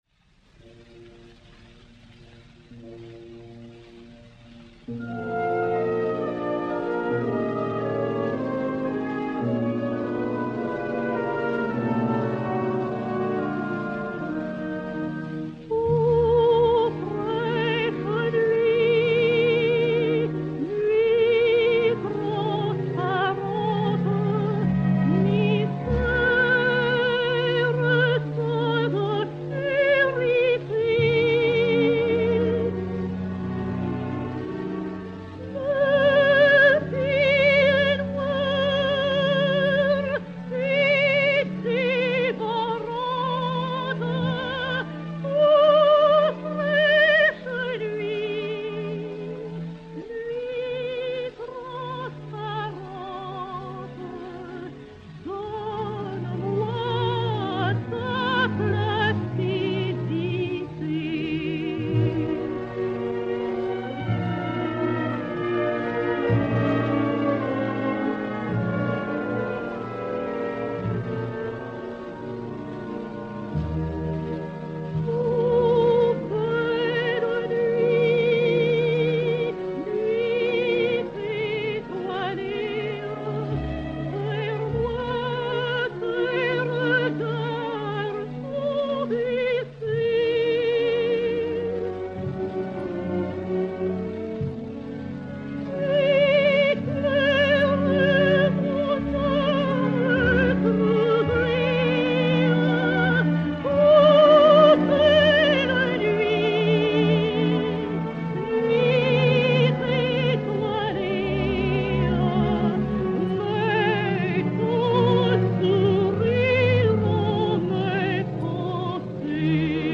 Germaine Martinelli (Méryem) et Orchestre dir. Albert Wolff